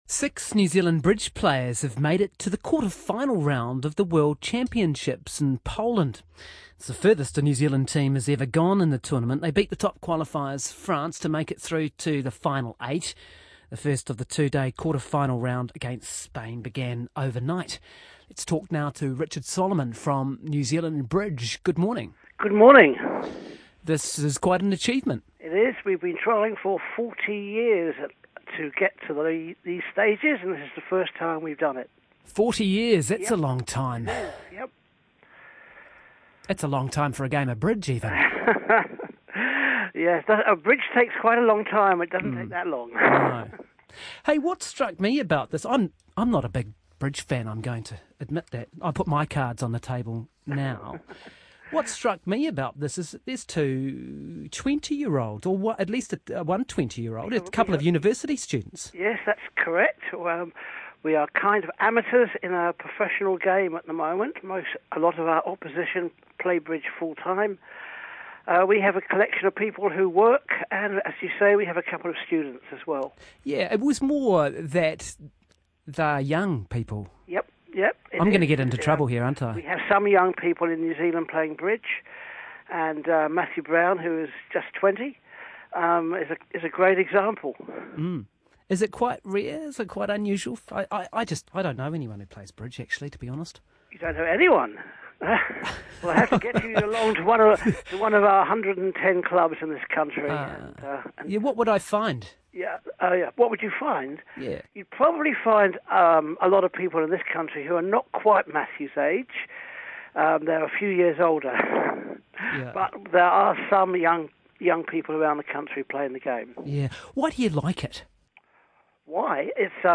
Also a radio interview, some photos and a video.
RNZ Interview re Poland 2016.mp3